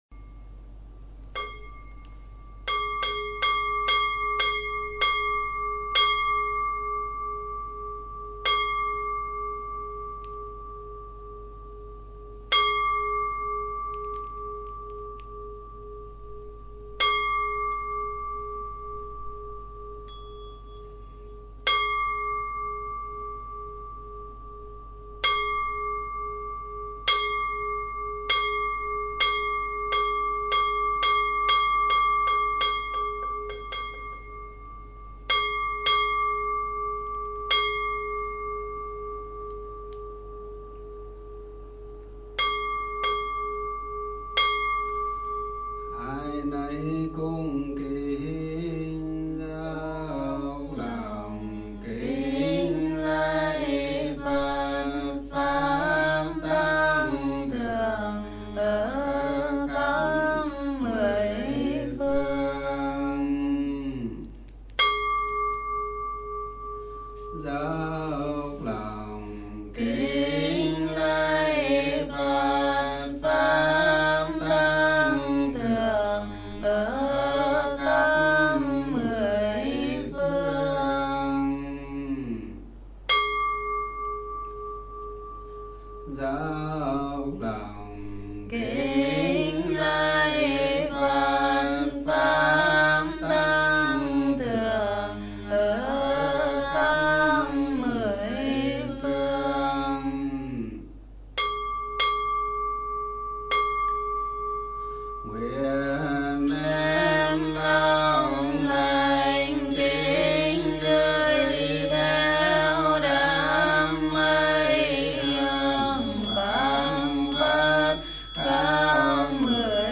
02. kinh-dia-tang-quyen-trung-tung-cham_64kbps.mp3